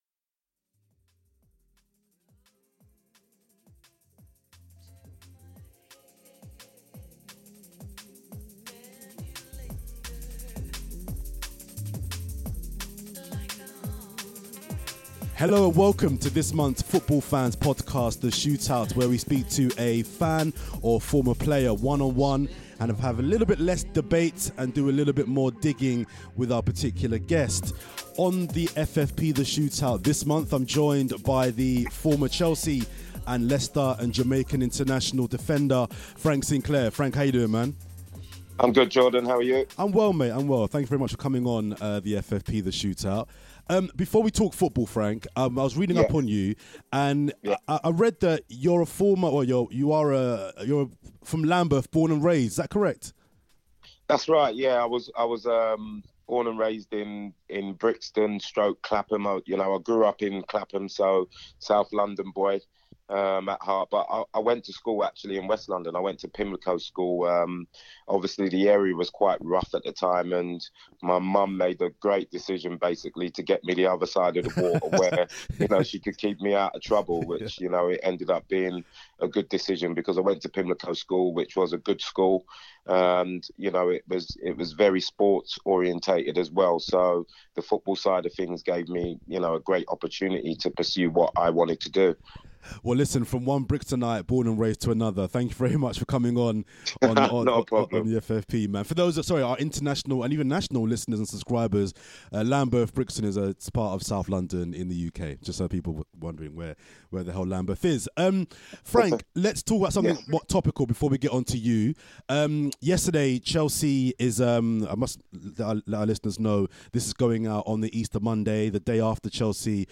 This month we speak to former Chelsea, Leicester and Jamaican international Frank Sinclair . He talks about managing in the lower leagues, punditry, and not being picked for England.